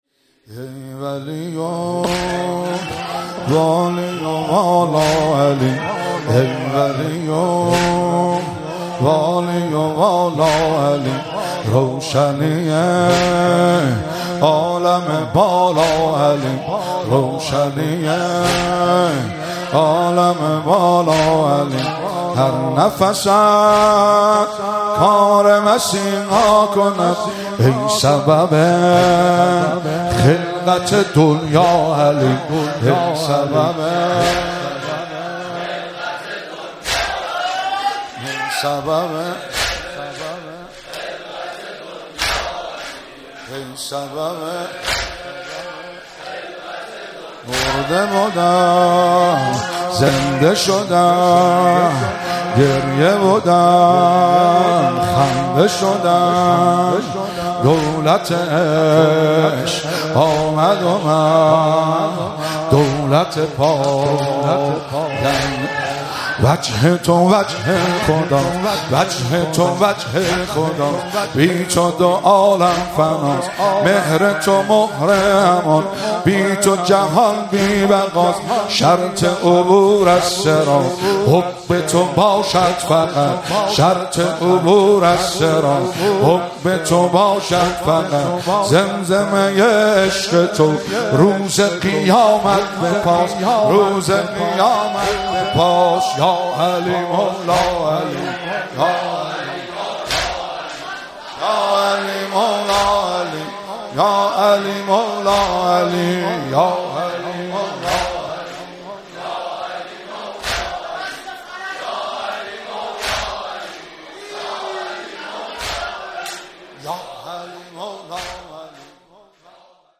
مراسم مناجات خوانی و احیای شب بیست و یکم و عزاداری شهادت حضرت امیرالمومنین علی علیه السلام ماه رمضان 1444